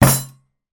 Звуки удара по столу
Посильнее и мощнее